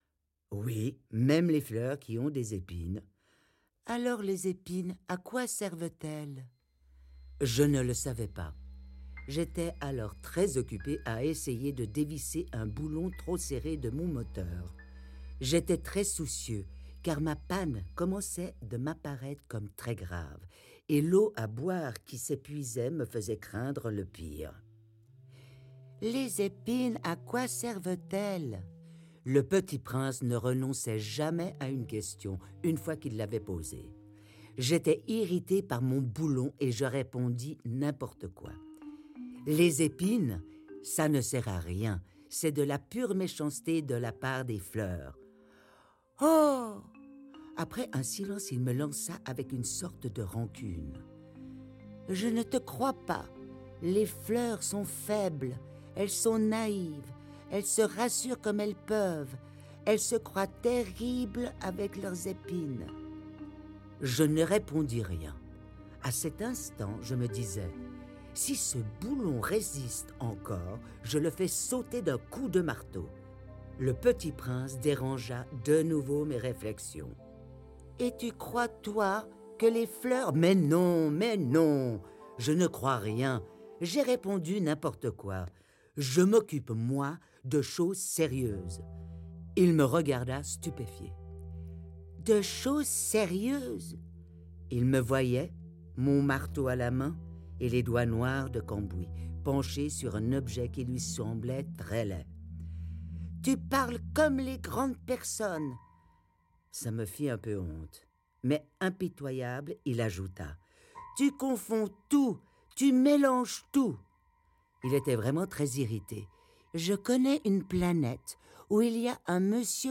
Le Petit Prince audiokniha
Ukázka z knihy